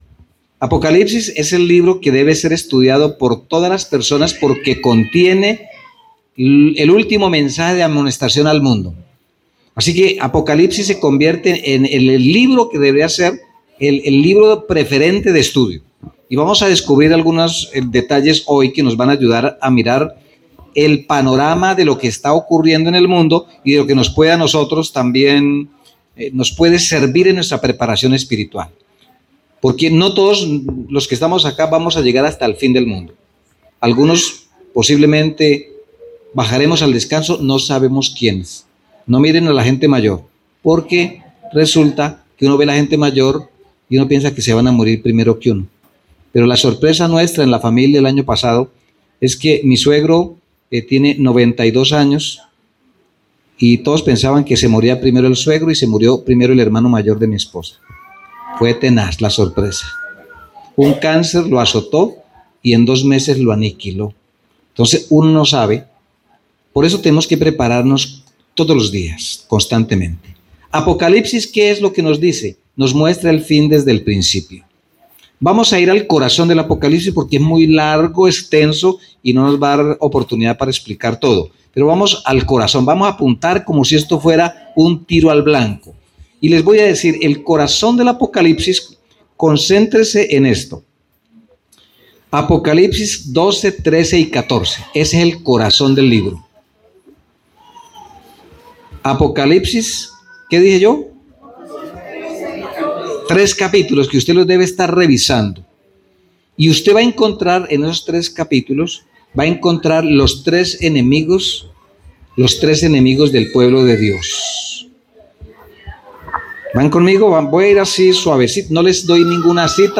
Predicación Primer Semestre 2026